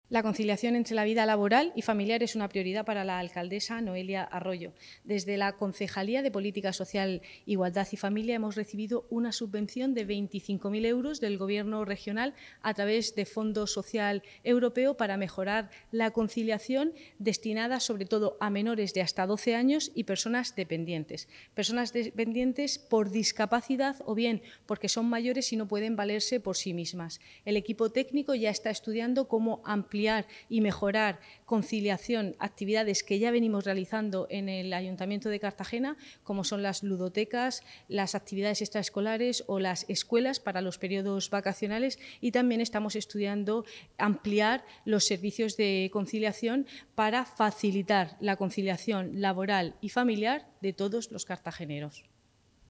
Enlace a Declaraciones de Cristina Mora, concejal del área de Política Social, Familia e Igualdad